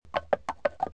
Horse 3 Bouton sonore